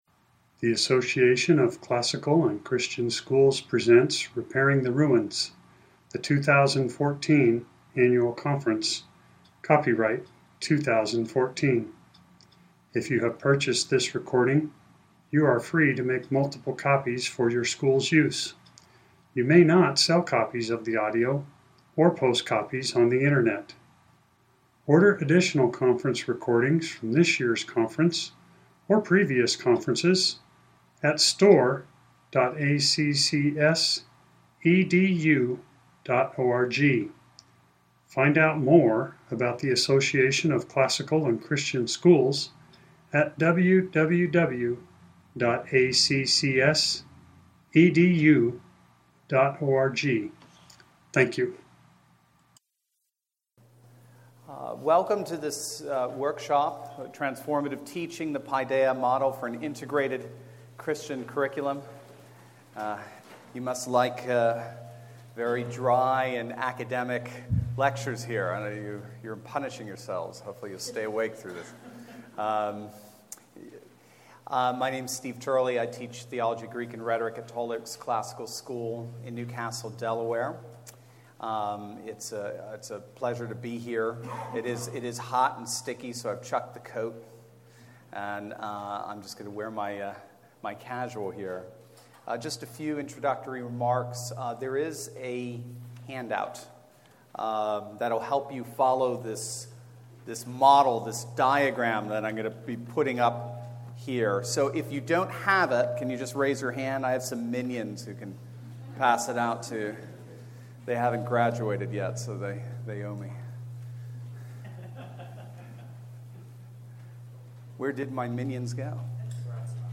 2014 Foundations Talk | 1:05:34 | All Grade Levels
Jan 19, 2019 | All Grade Levels, Conference Talks, Library, Media_Audio, Workshop Talk | 0 comments